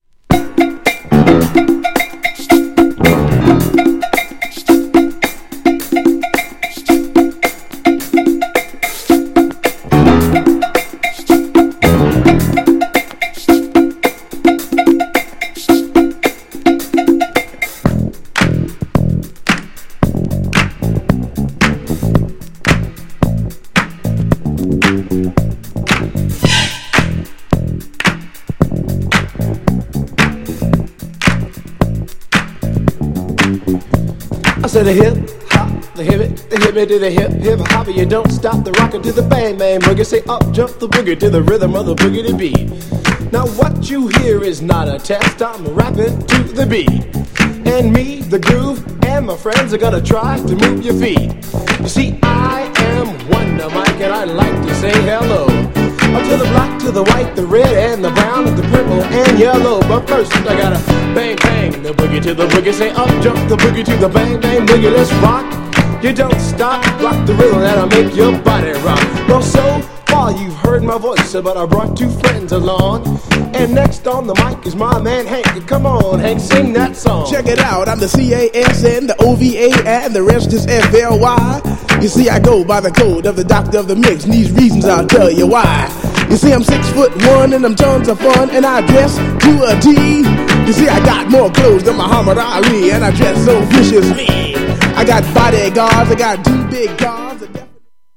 ヒップホップ